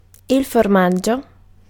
Ääntäminen
US : IPA : [tʃiːz]